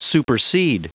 Prononciation du mot supersede en anglais (fichier audio)
Prononciation du mot : supersede